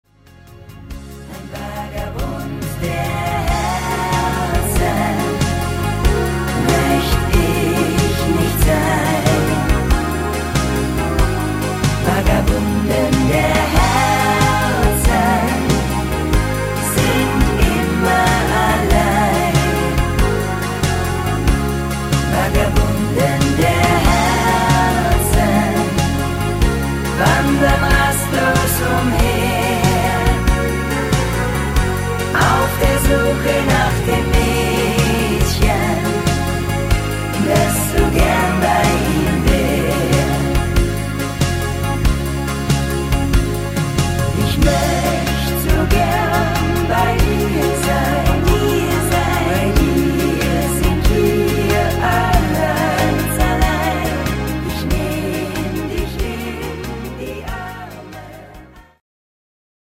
Rhythmus  Slowrock
Art  Deutsch, Volkstümlicher Schlager